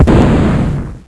snd_132_humanExplode.wav